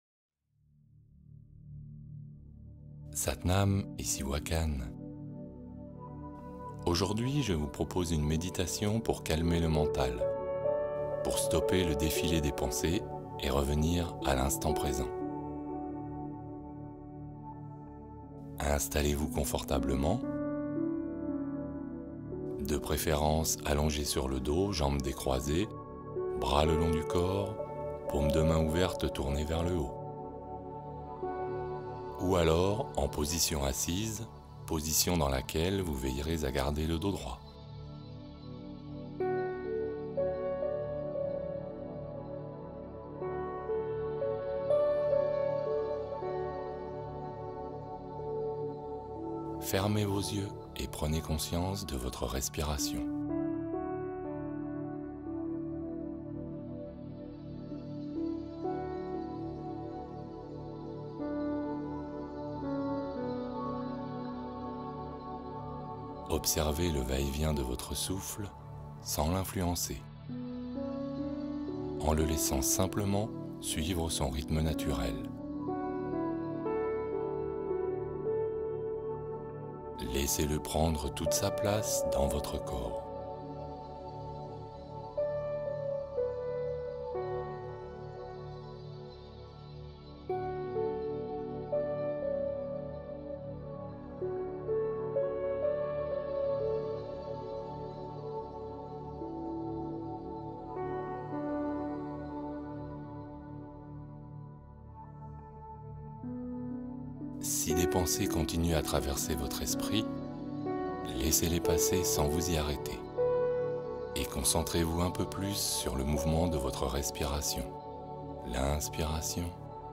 Bols tibétains : expérience sonore pour apaiser les peurs et l’énergie